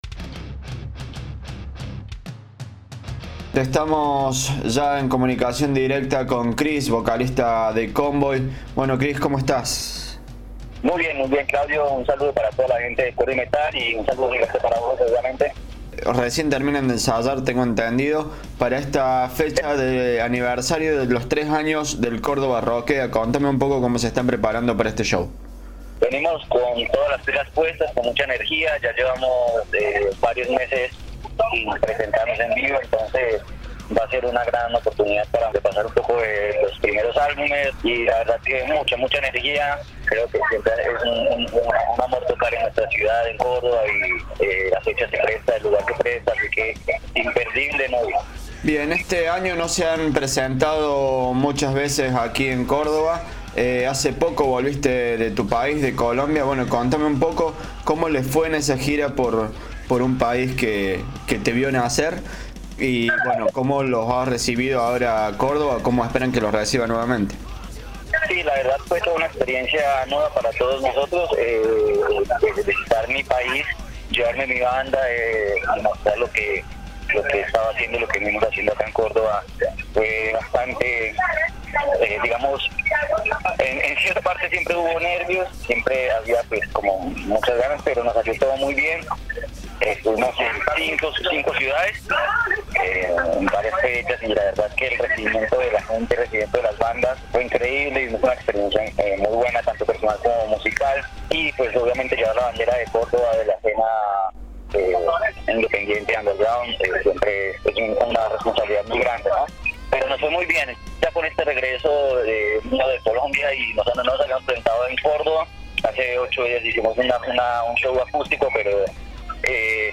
Entrevista-Convoi.mp3